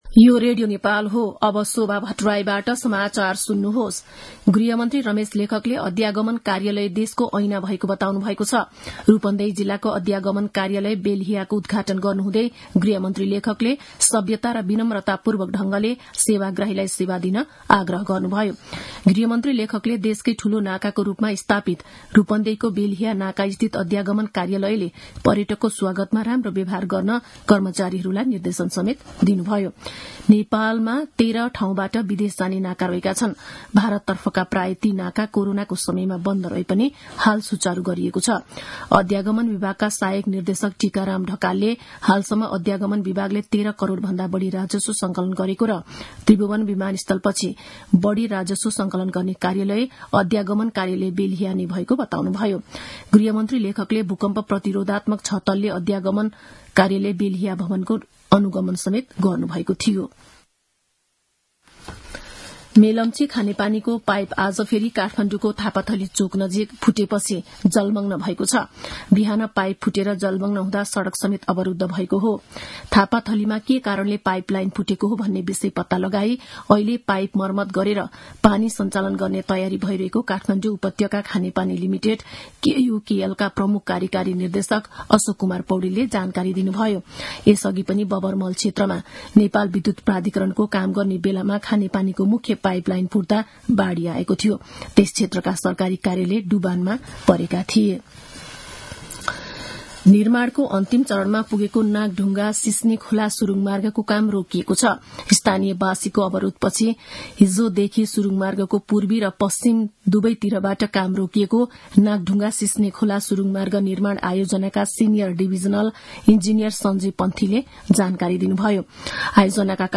मध्यान्ह १२ बजेको नेपाली समाचार : २१ फागुन , २०८१
12-am-news-1-1.mp3